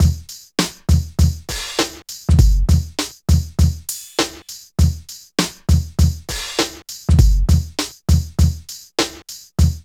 60 DRUM LP-R.wav